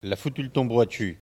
Elle provient de Saint-Gervais.
Locution ( parler, expression, langue,... )